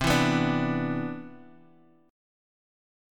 CM9 chord